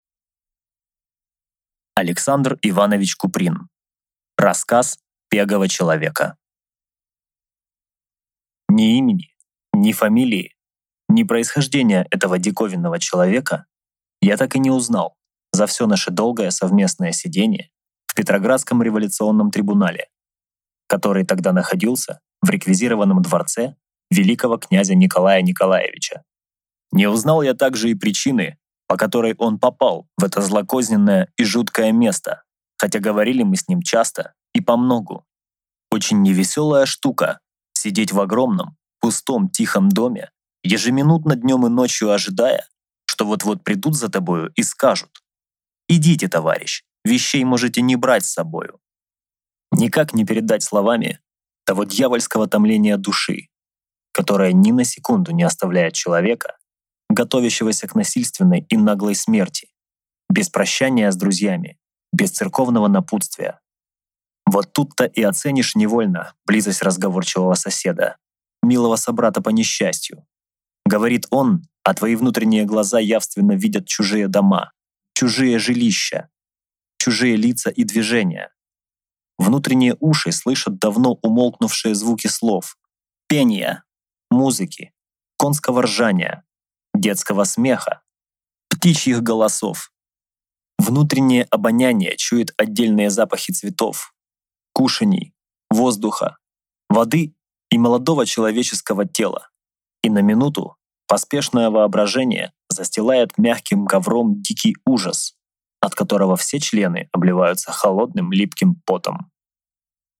Аудиокнига Рассказ пегого человека | Библиотека аудиокниг